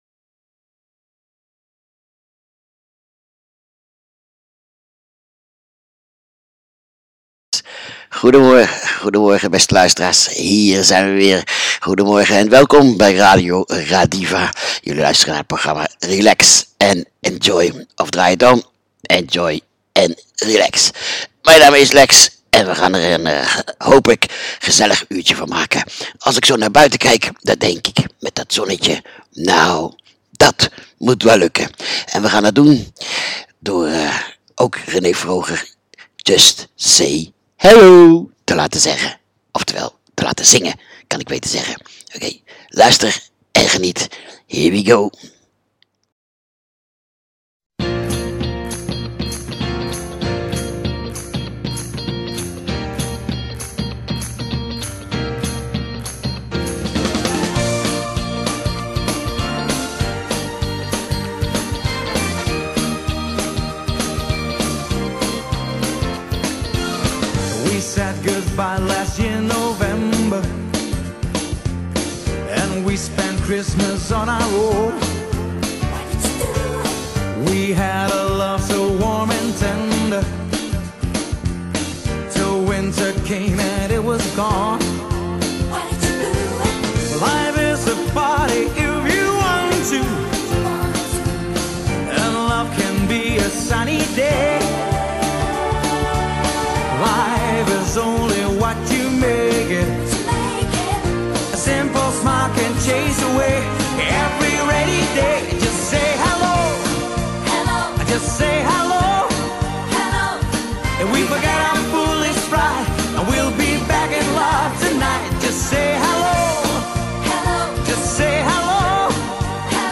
Het enige programma waar je de week ontspannen mee begint! Dit is een uitzending in vakantiestyle , veel muziek en alleen verkeersinfo.